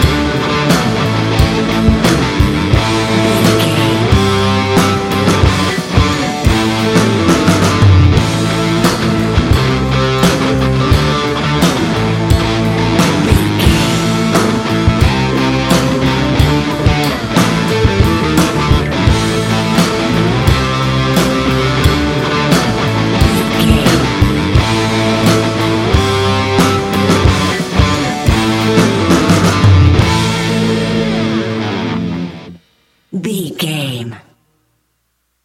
Ionian/Major
A♭
hard rock
heavy rock
blues rock
distortion
instrumentals